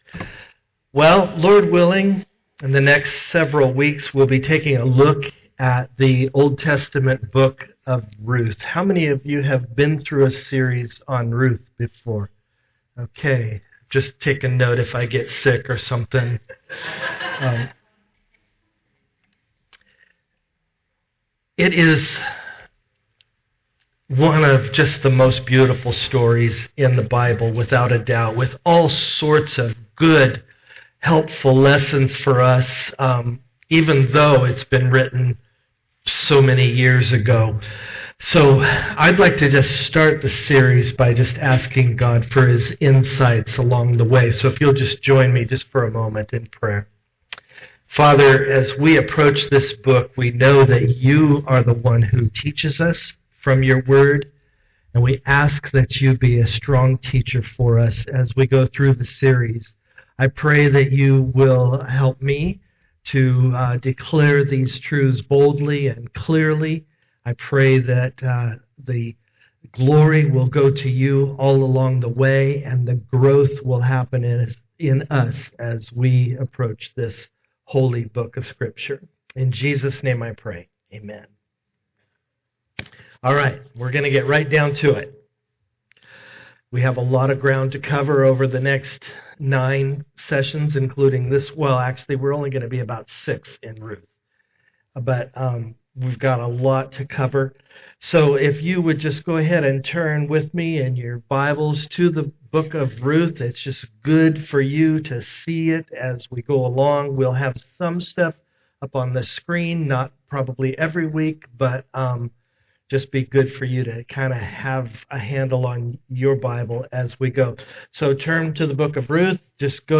Service Type: am worship